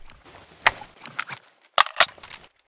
ak74_reload.ogg